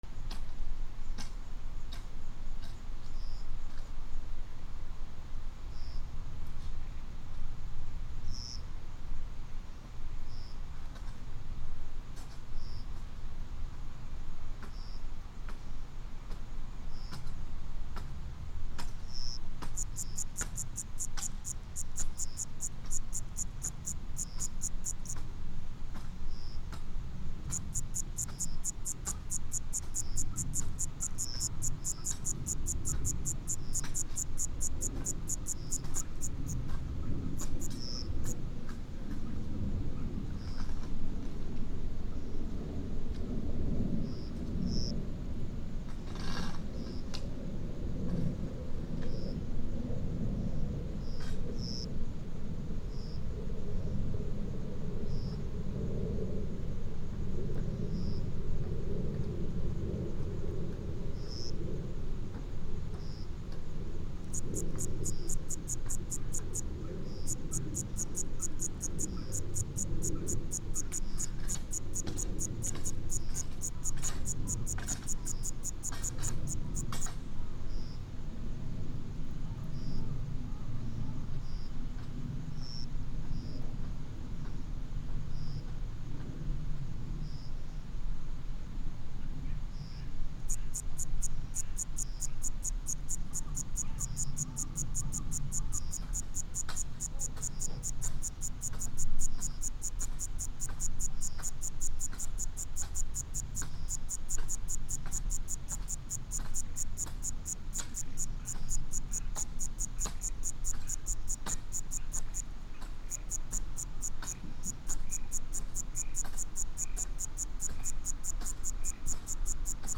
農作業と虫の鳴き声
/ B｜環境音(自然) / B-30 ｜虫の鳴き声 / 虫20_虫の鳴き声20_山・森林